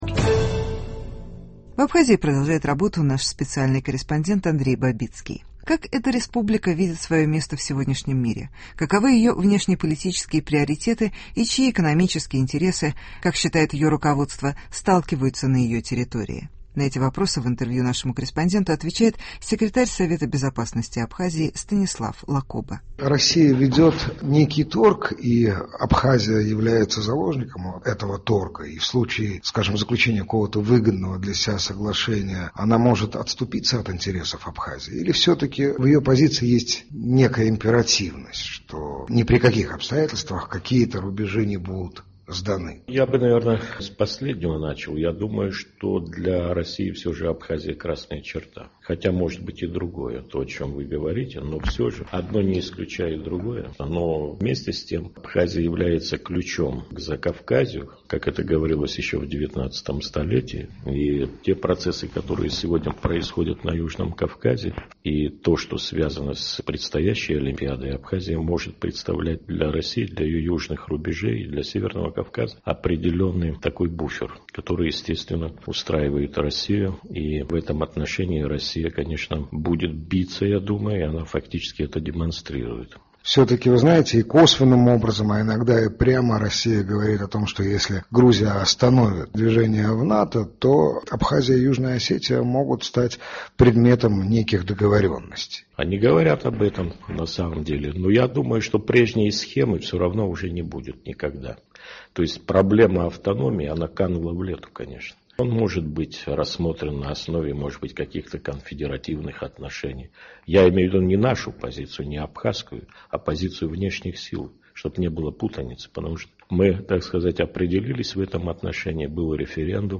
В чем состоят внешнеполитические приоритеты Абхазии. Беседа с секретарем Совета безопасности республики.